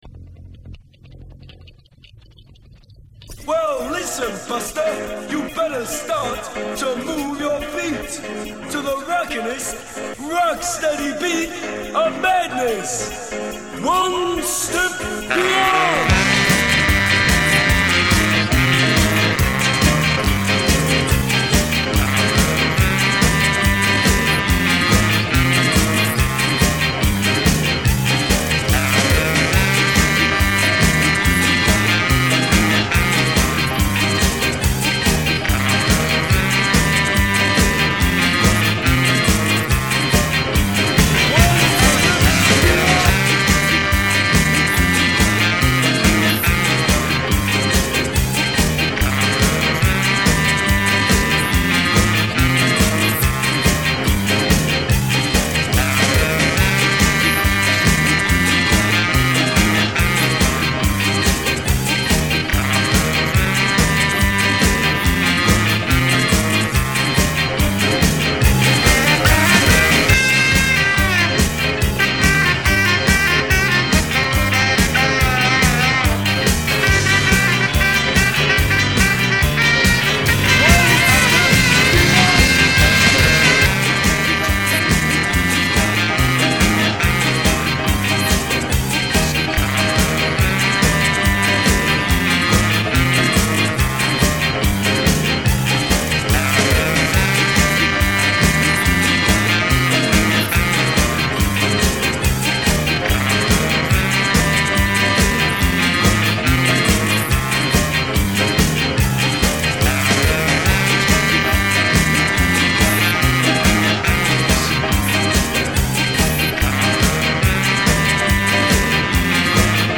March 27th, 2006 at 6:32PM in bootleg